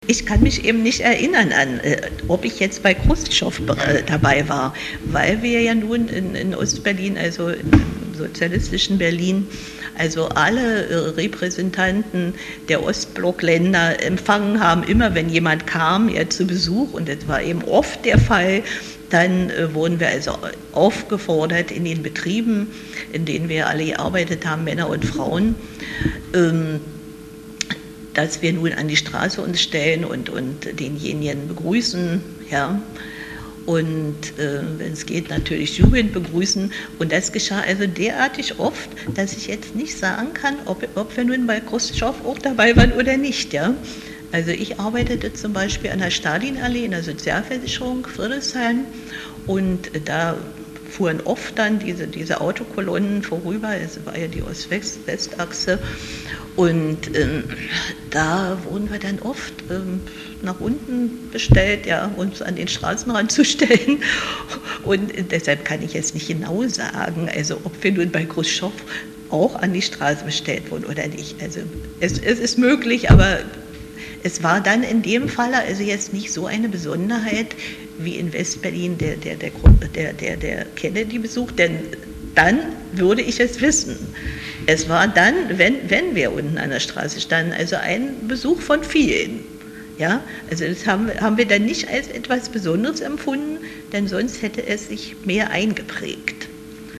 Obwohl beide unzufrieden mit der politischen Führung der DDR waren, zeigten sie in einem Interview auf Fragen zum Kennedy-Besuch sehr unterschiedliche Meinungen.